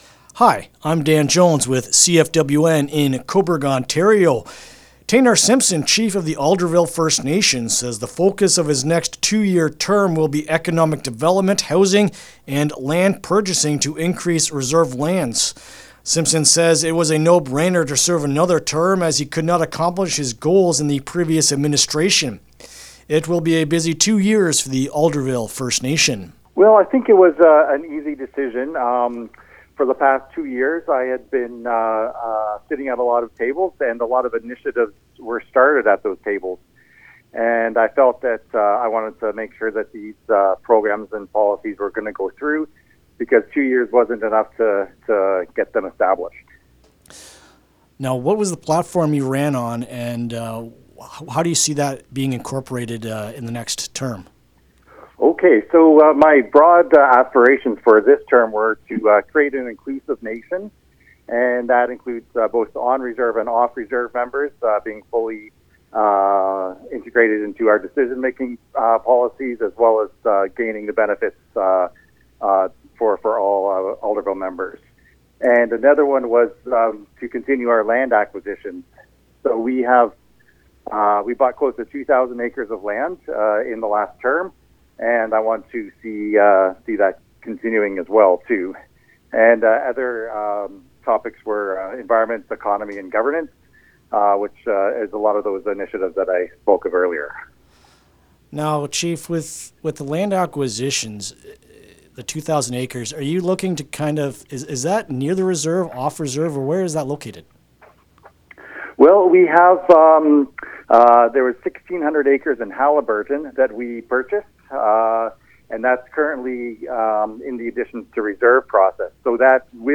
Taynar-Simpson-LJI-interview.mp3